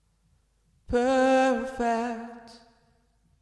描述：舞蹈声乐循环
Tag: 140 bpm Dance Loops Vocal Loops 590.75 KB wav Key : Unknown